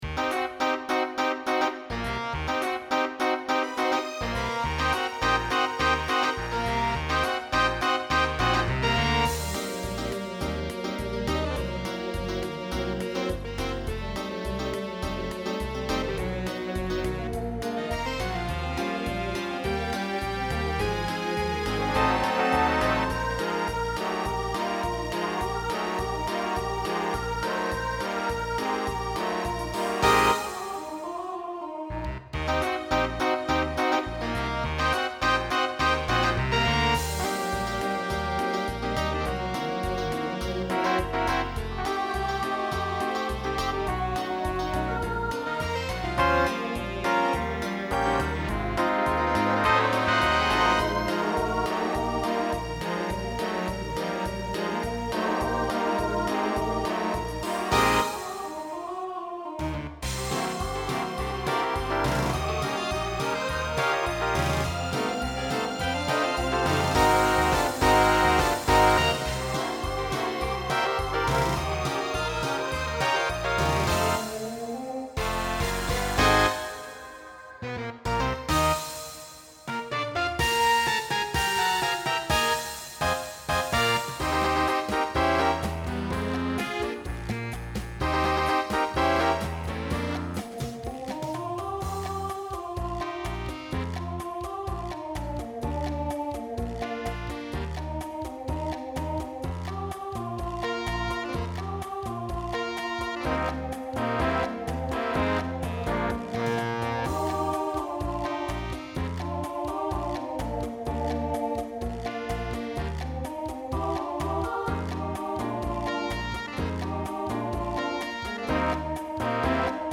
Voicing Mixed Instrumental combo
Swing/Jazz